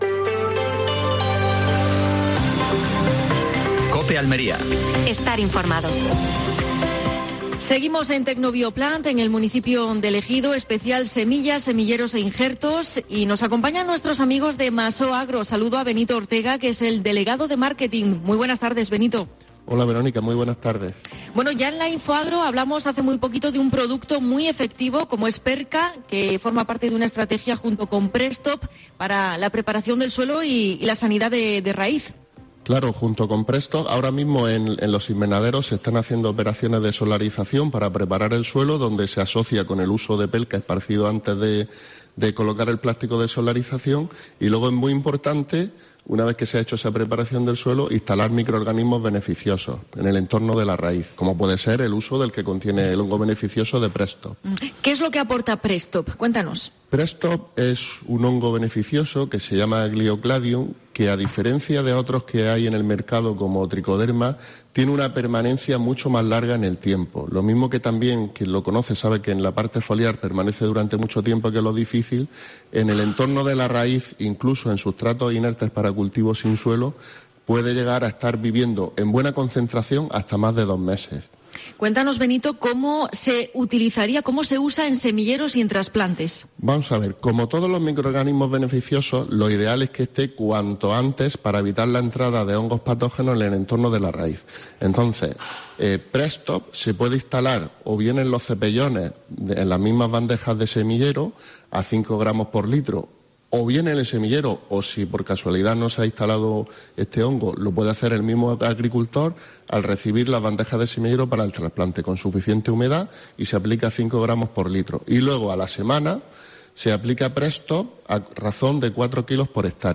AUDIO: Última hora en Almería. Especial semillas.